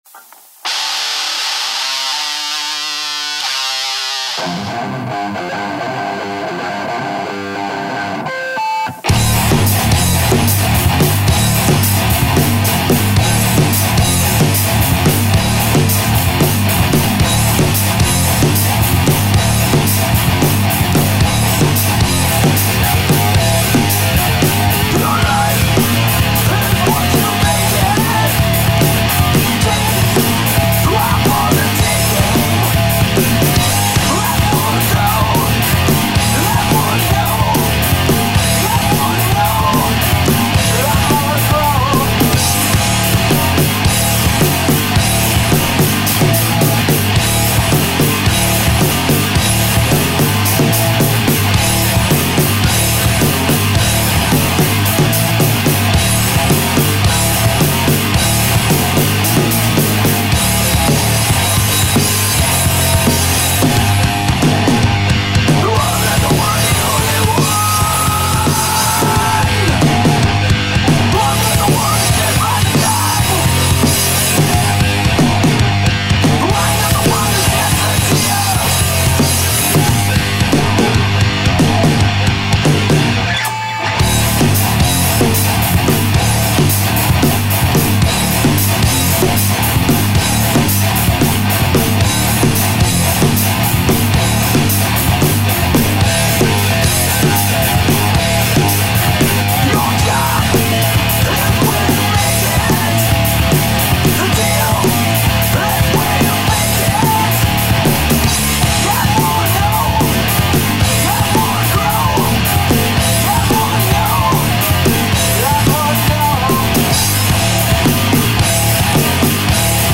Genre: Punk